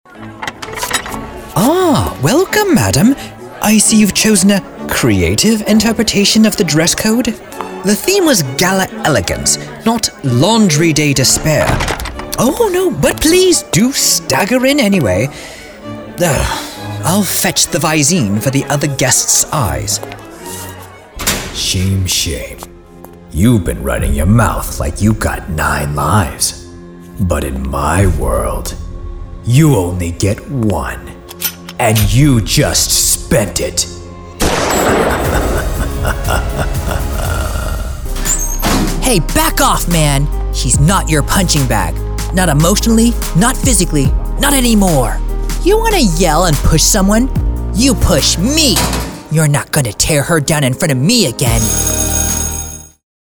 Character Demo
Character: Multiple Accents, Various Age Range, Multiple Genre Types, Believability, Authentic, Dynamic, Theatrical, Improvisational, Funny, Strong, Committed, Expressive, Dubbing/Translation and Animated.